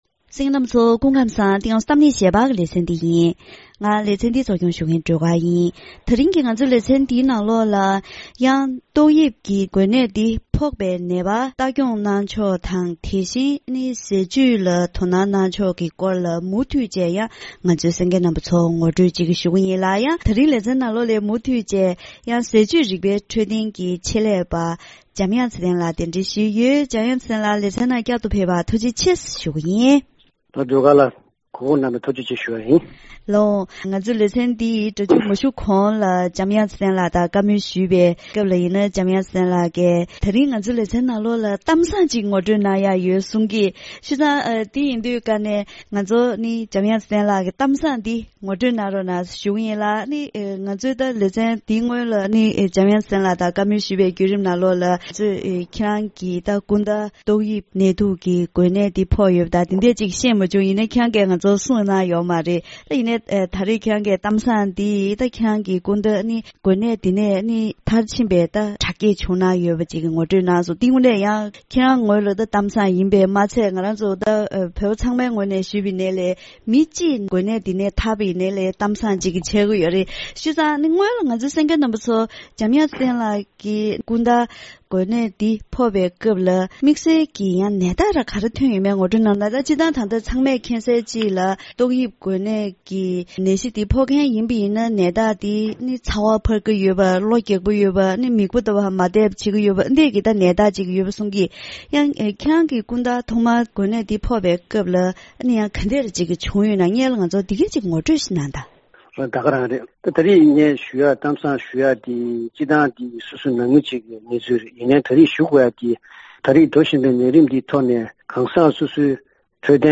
ད་རིང་གི་གཏམ་གླེང་ཞལ་པར་ལེ་ཚན་ནང་ཏོག་དབྱིབས་ནད་དུག་གི་འགོས་ནད་འཛམ་གླིང་ནང་ཁྱབ་གདལ་ཕྱིན་པའི་སྐབས་དེར་འགོས་ནད་ཕོག་མཁན་ལ་ཁྱིམ་ཚང་ནས་ལྟ་སྐྱོང་གནང་ཕྱོགས་དང་དམིགས་བསལ་འཚོ་བཅུད་C དང་Dབེད་སྤྱོད་གནང་ཕྱོགས་སོགས་ཀྱི་ཐོག་ཆེད་ལས་མི་སྣ་དང་ལྷན་དུ་བཀའ་མོལ་ཞུས་པ་ཞིག་གསན་རོགས་གནང་།